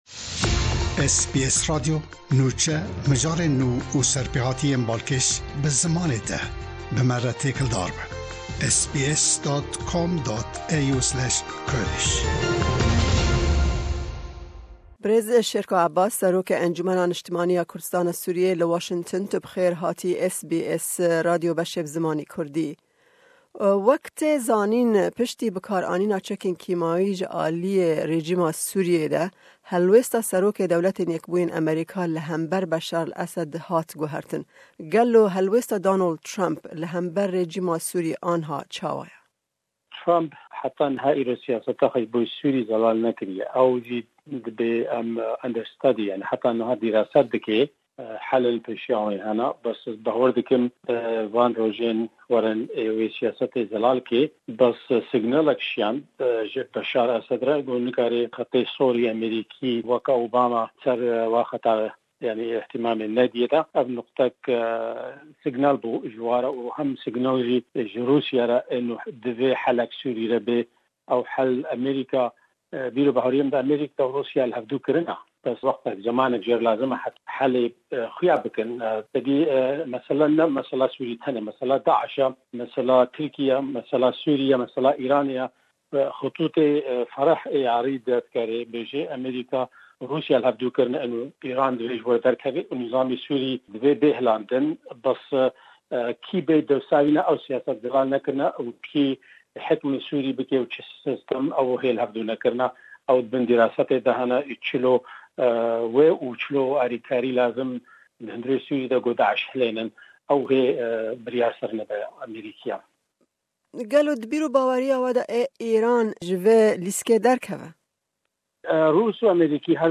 Me hevpeyvînek derbarê rewsha li Sûriyê û bi karanîna chekên kîmawi ku rêcîma Sûrîyê li Xan Shêxûn bi kar anî, helwêsta DYA li hember Suriyê, êrîsha Turkiyê ya vê dawiyê û babetên din di hevpeyvê de hene.